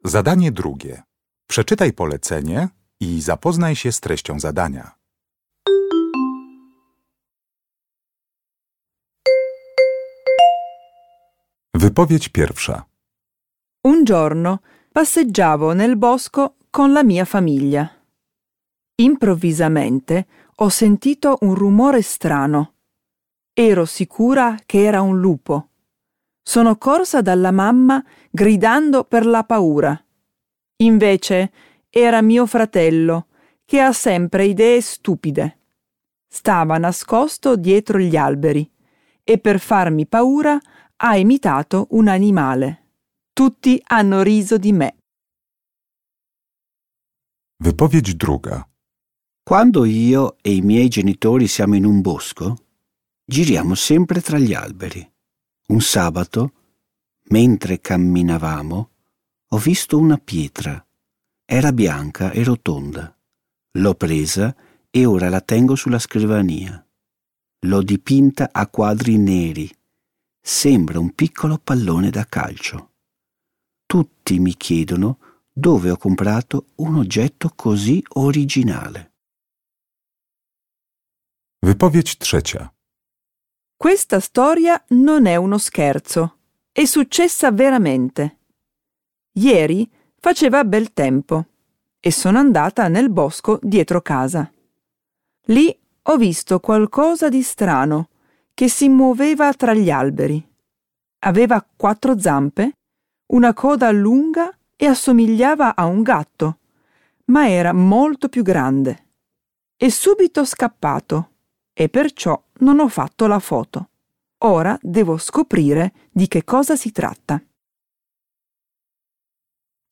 Uruchamiając odtwarzacz z oryginalnym nagraniem CKE usłyszysz dwukrotnie cztery wypowiedzi na temat wycieczek do lasu.